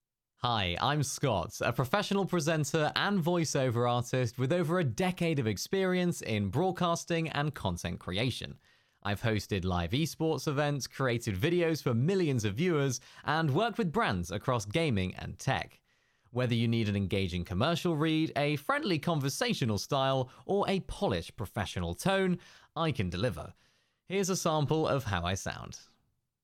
Adult (30-50) | Yng Adult (18-29)